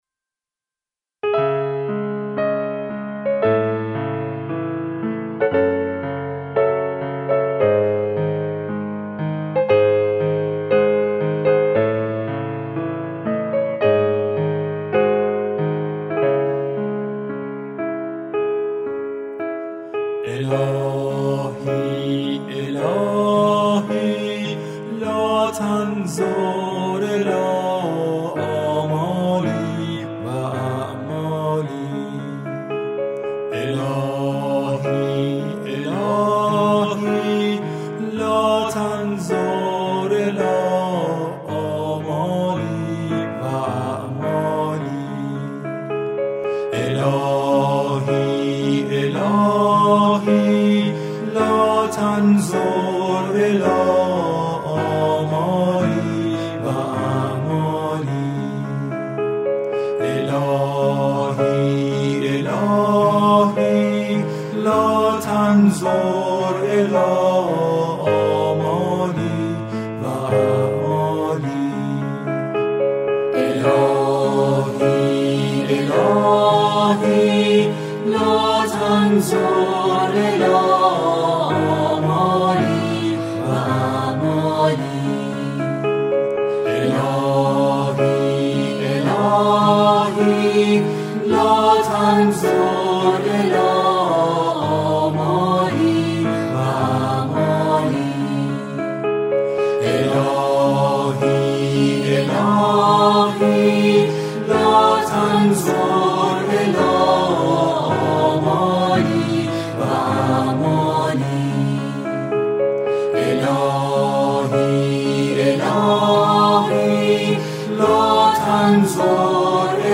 دعا و نیایش با موسیقی 2